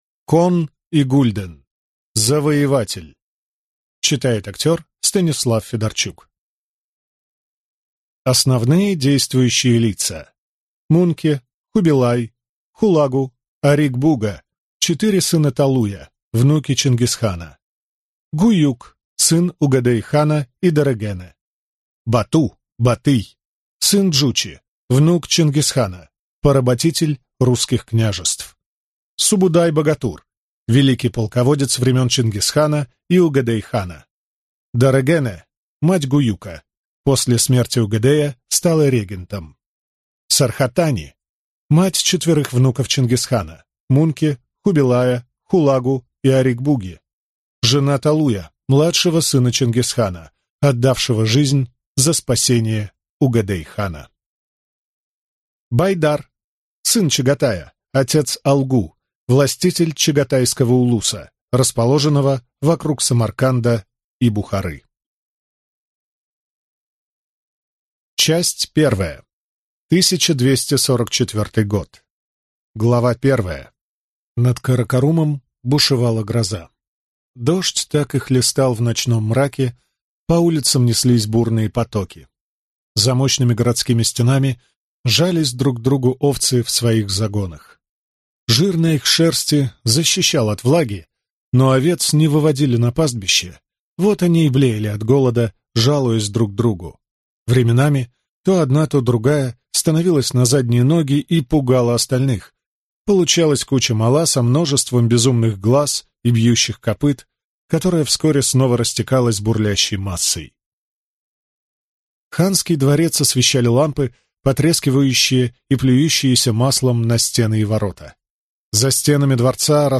Аудиокнига Завоеватель | Библиотека аудиокниг